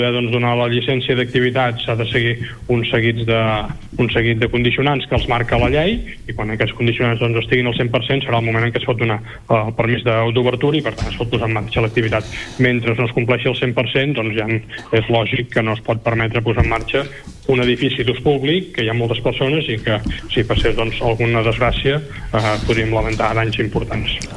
En una entrevista a LA CIUTAT, se li ha preguntat a l’alcalde Marc Buch perquè encara no s’ha obert l’Hotel Vila, tot i que aparentment hagin acabat les obres de reforma.